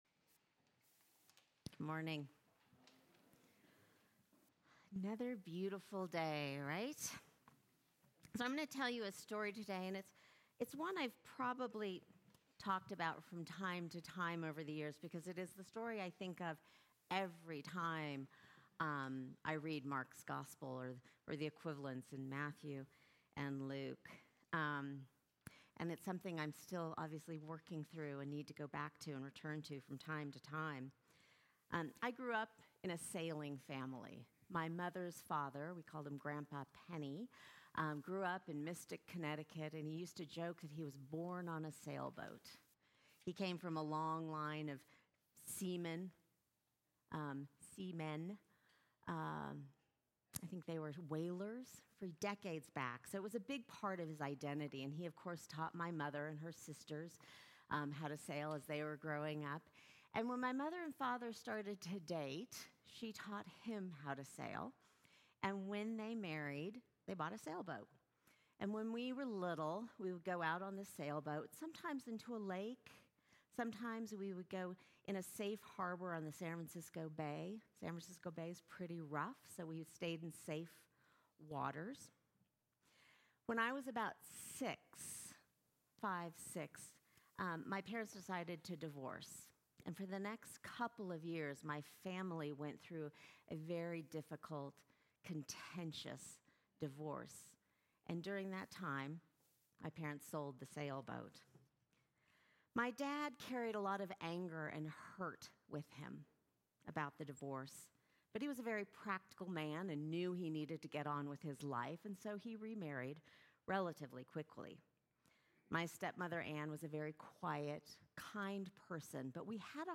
Sermons from St. John's Episcopal Church Fifth Sunday after Pentecost